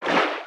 Sfx_creature_trivalve_swim_fast_03.ogg